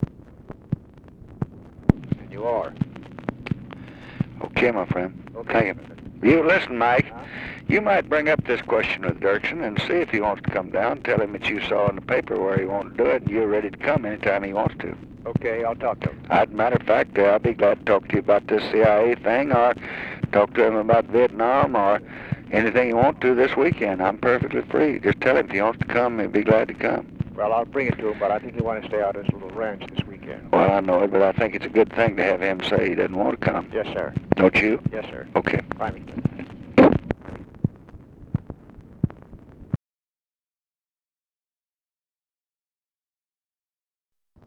Conversation with MIKE MANSFIELD, June 10, 1966
Secret White House Tapes